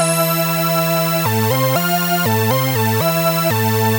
Synth 40.wav